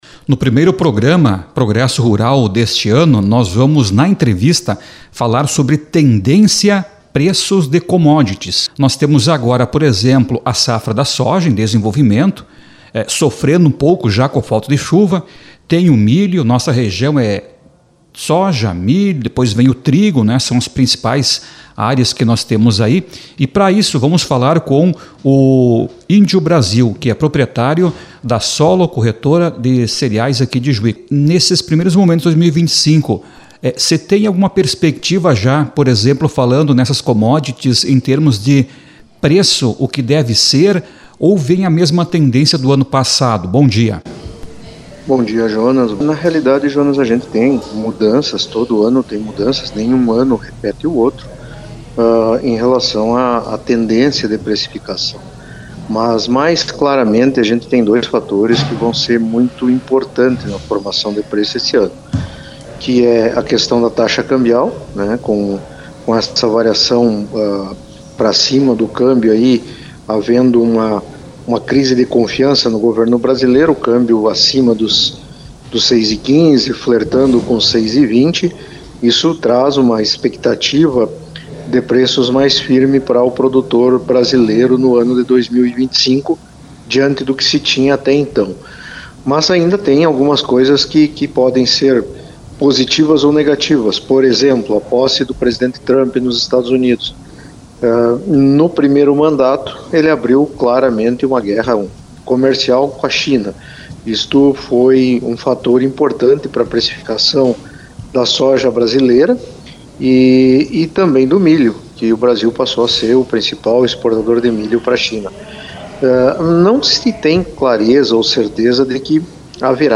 ENTREVISTA-PROGRESSO-RURAL-05.mp3